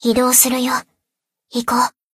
贡献 ） 分类:蔚蓝档案语音 协议:Copyright 您不可以覆盖此文件。
BA_V_Shiroko_Ridingsuit_Battle_Move_1.ogg